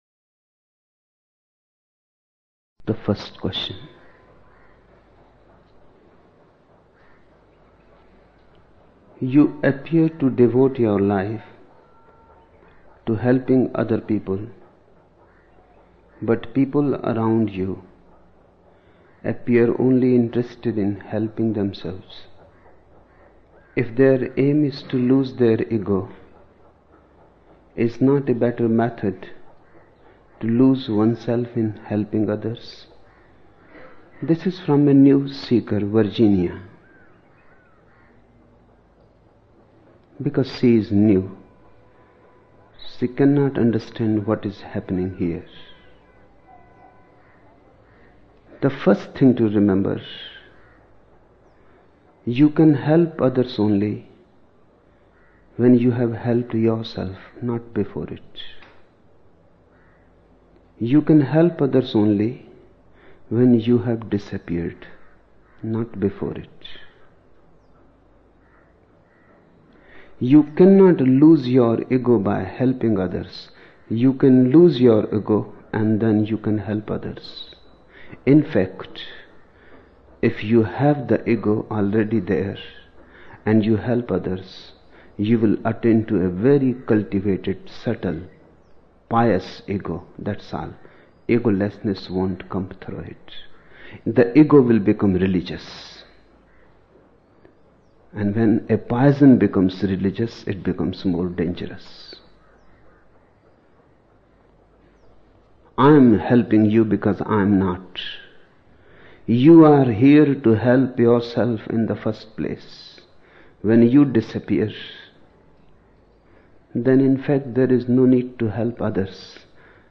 Chapter title: Eli, Eli, Lamasabachthani 29 December 1975 am in Buddha Hall